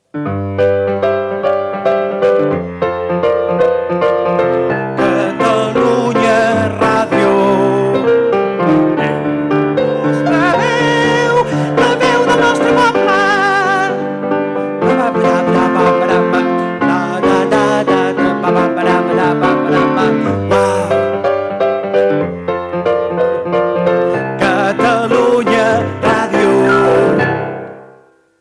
Maquetes, intrerpretades al piano